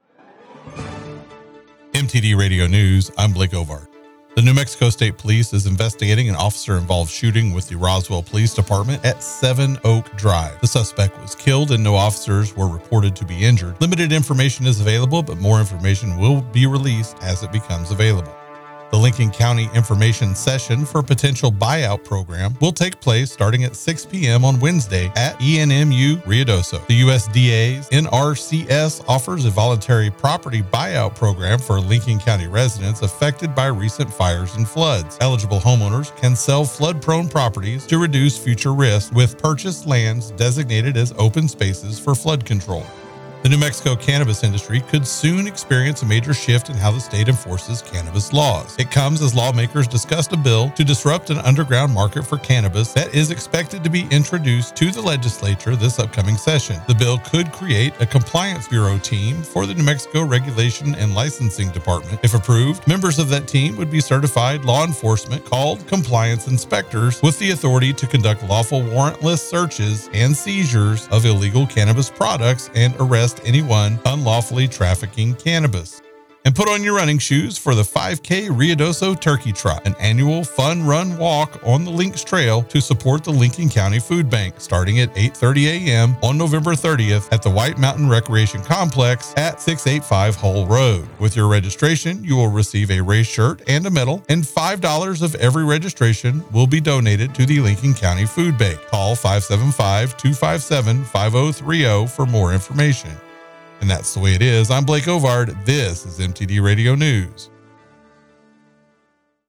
KIDX NEWS RUIDOSO AND LINCOLN COUNTY